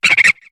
Cri de Feuillajou dans Pokémon HOME.